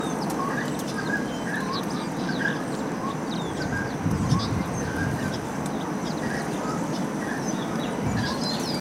Chiricote (Aramides cajaneus)
Nombre en inglés: Grey-cowled Wood Rail
Fase de la vida: Adulto
Localidad o área protegida: Reserva Ecológica Costanera Sur (RECS)
Condición: Silvestre
Certeza: Vocalización Grabada
Chiricote.mp3